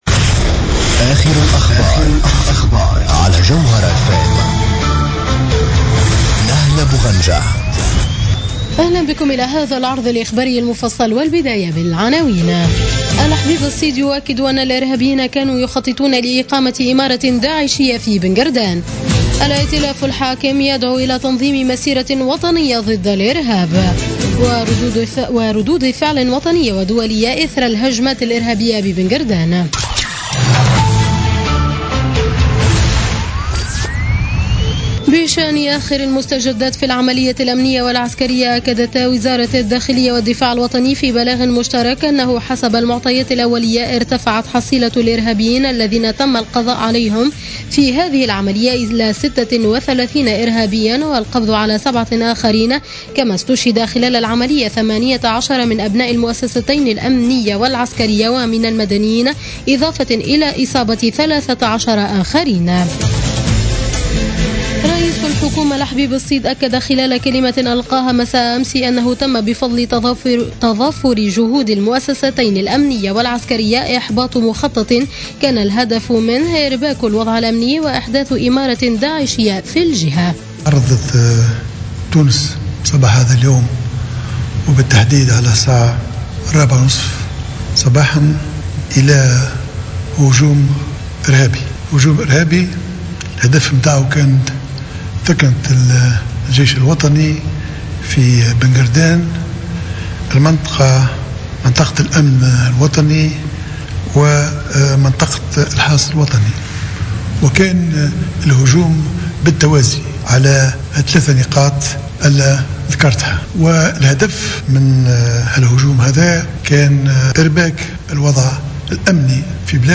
نشرة أخبار منتصف الليل ليوم الثلاثاء 8 مارس 2016